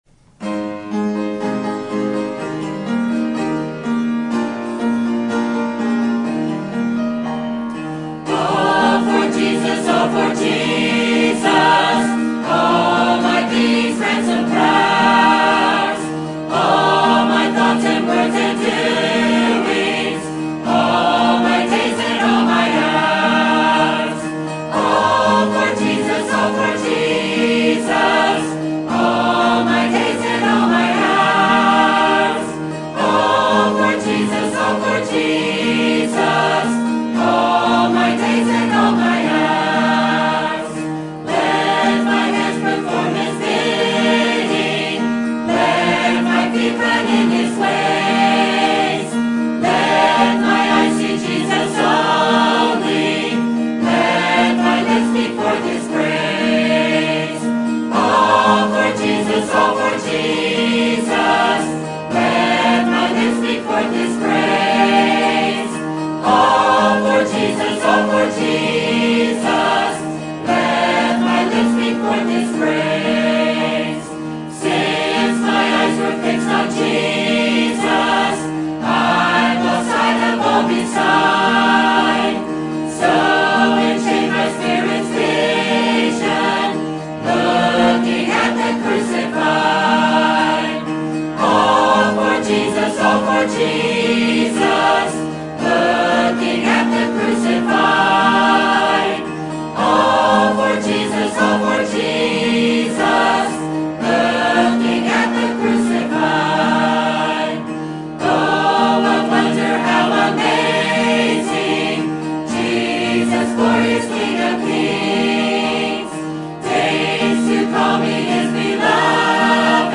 Sermon Topic: Missions Conference Sermon Type: Special Sermon Audio: Sermon download: Download (27.83 MB) Sermon Tags: 1 Corinthians Grace Missions Salvation